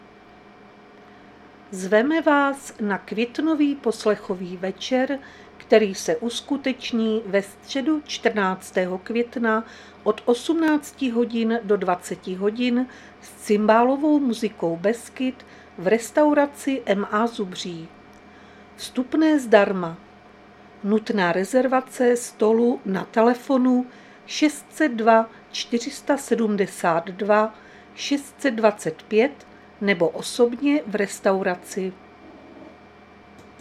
Záznam hlášení místního rozhlasu 13.5.2025